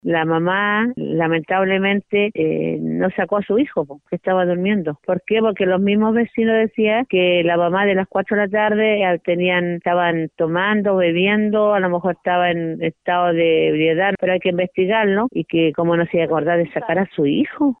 Por su parte la alcaldesa de Nogales, Margarita Osorio, indicó que la familia tiene nacionalidad ecuatoriana.
cu-incendio-nogales-alcaldesa-nogales-.mp3